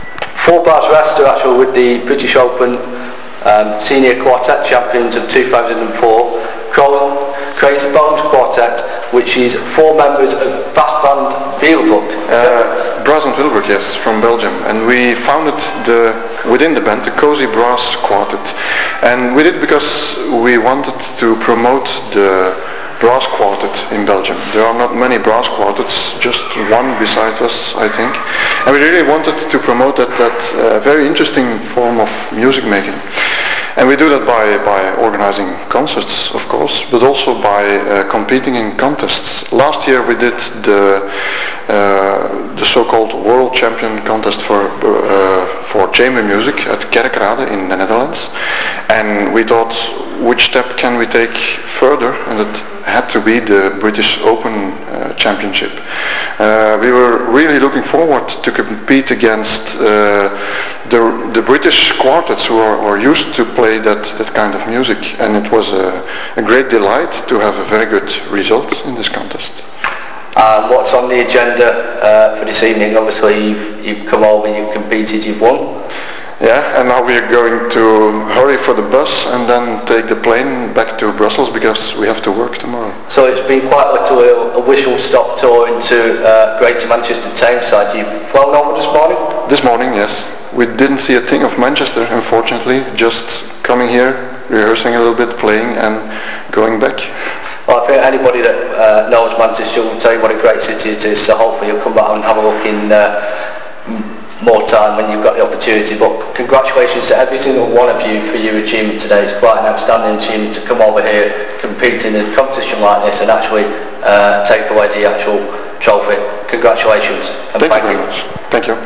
Hyde Town Hall, Sunday 7th November 2004
Audio interviews
Interview with Williebroek Quartet - Cozy Bones  [WAV 497kb]